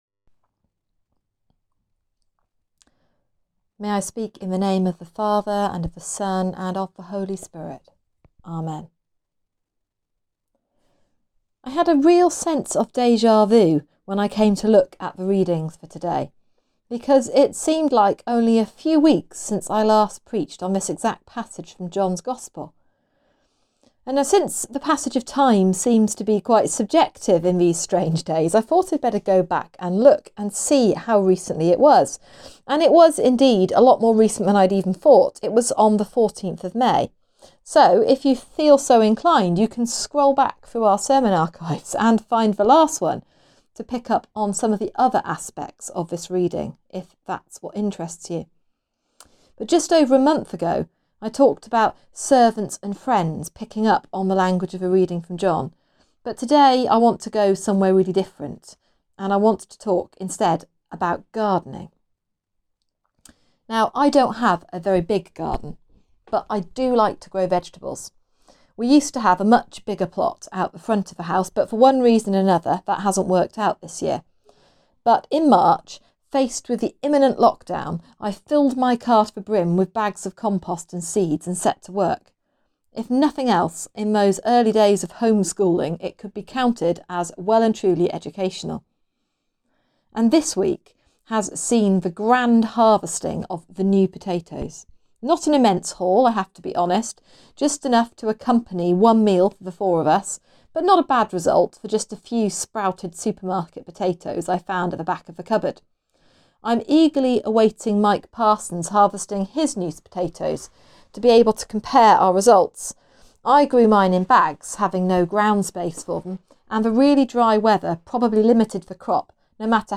Sermon: Fruit that Lasts | St Paul + St Stephen Gloucester
Sermon-11.6.20-Fruit-that-Lasts.mp3